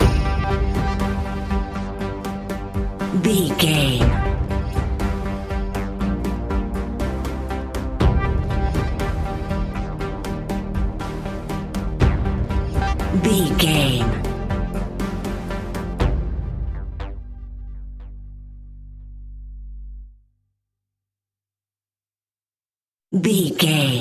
Aeolian/Minor
ominous
dark
eerie
synthesiser
drum machine
instrumentals
horror music